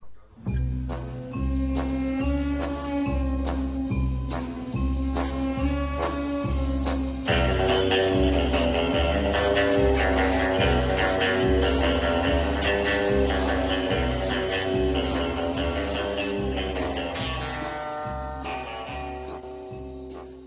intro to the second Tanga show, in real reel audio.